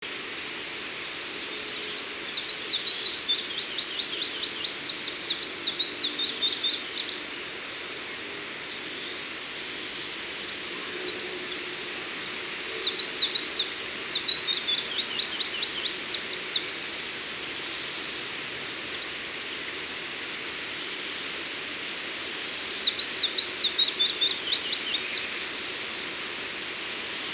Sekalaulava uunilintu / A song switching Phylloscopus warbler
Äänite 5 Pajulintumaista laulua Recording 5 Willow Warbler-like song